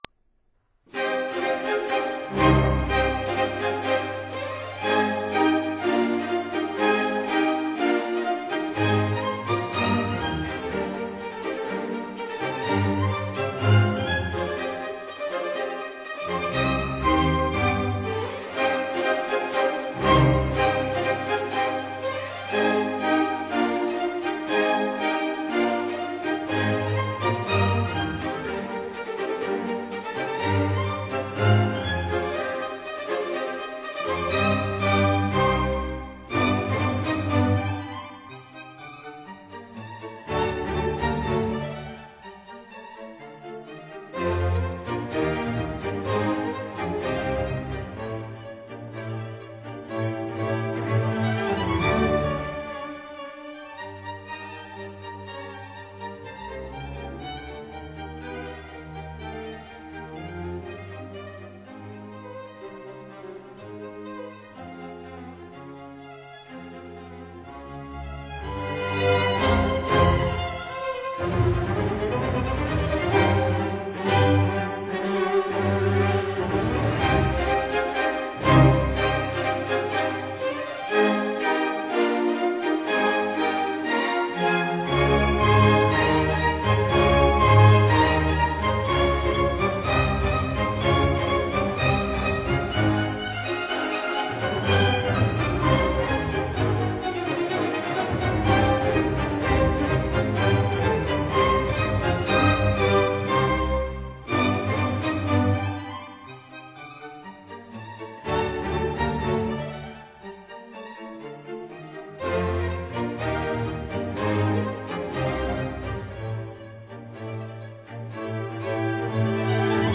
其作品為浪漫主義風格，受勃拉姆斯影響較大。